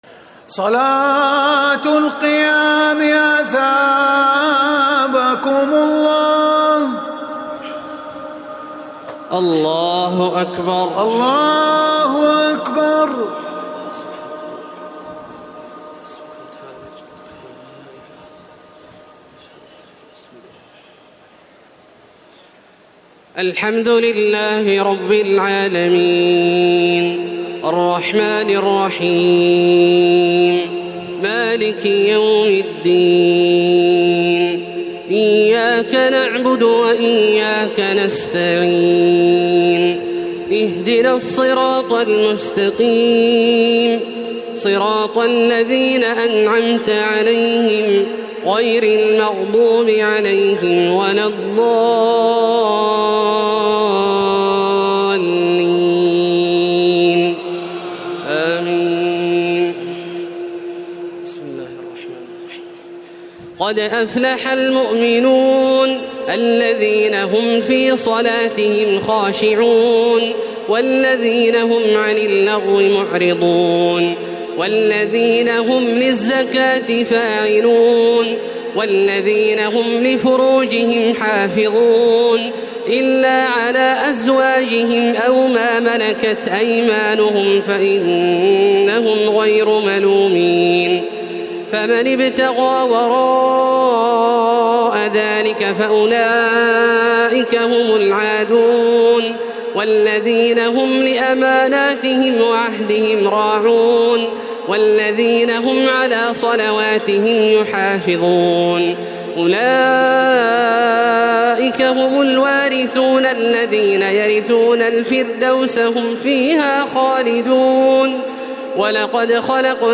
تراويح الليلة السابعة عشر رمضان 1432هـ سورتي المؤمنون و النور (1-20) Taraweeh 17 st night Ramadan 1432H from Surah Al-Muminoon and An-Noor > تراويح الحرم المكي عام 1432 🕋 > التراويح - تلاوات الحرمين